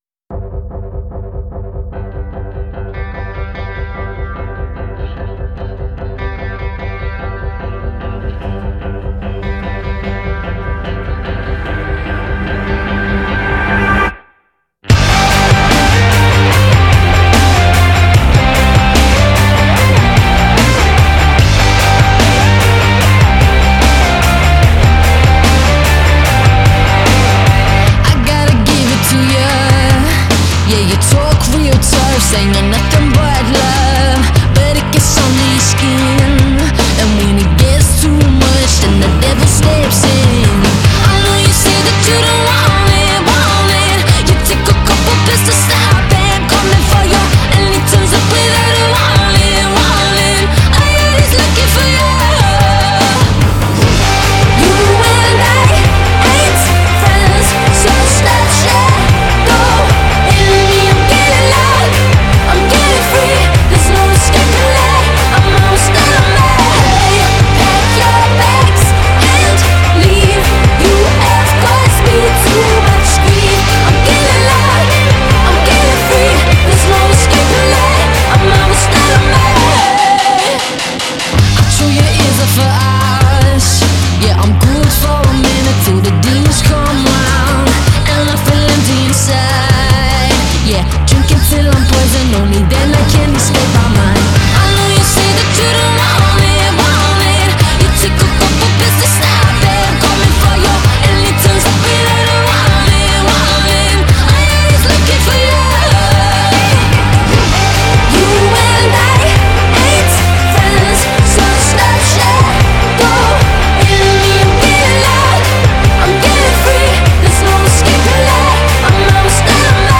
Genre: Rock